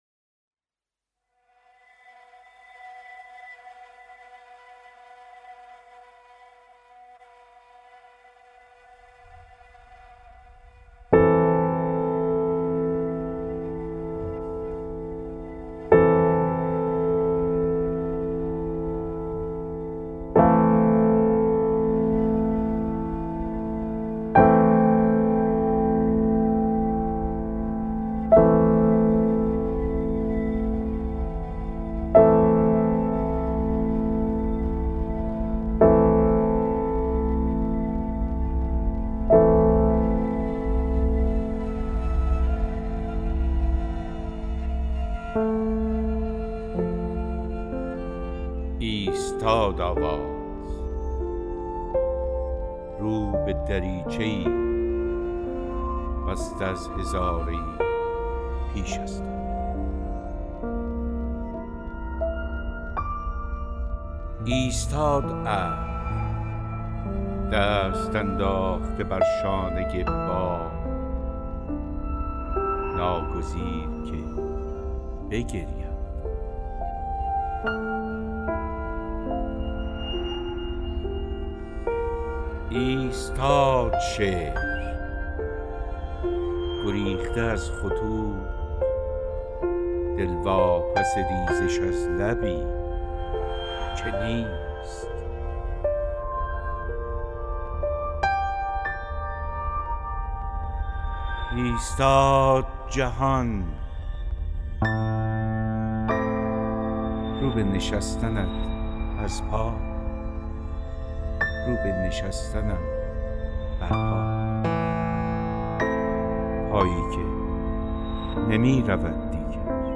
دانلود دکلمه آستانه با صدای حسین پاکدل با متن دکلمه
گوینده :   [حسین پاکدل]